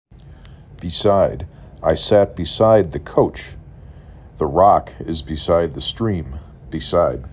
6 Letters, 2 Syllable
b i s I d
b E s I d